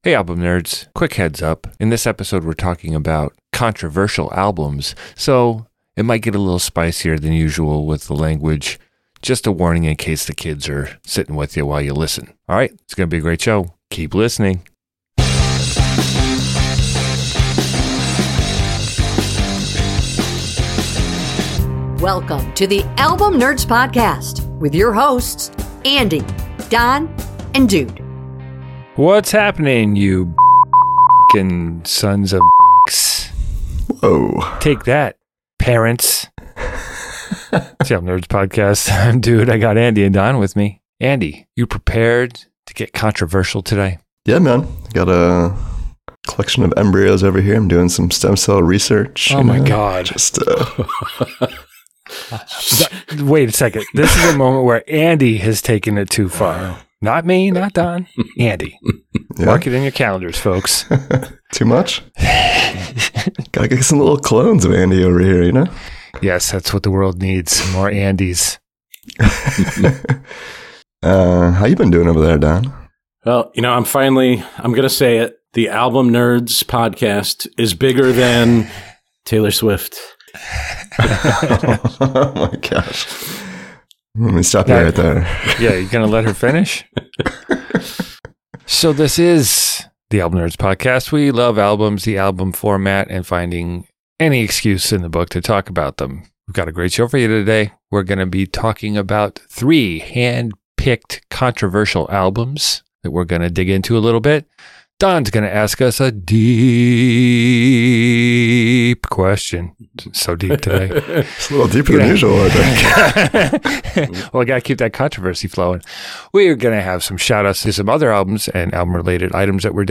Album picks on a range of topics selected by the all knowing Wheel of Musical Destiny. Three friends and music nerds discuss classic albums across a variety of genres including rock, metal, country, hip-hop, rnb and pop.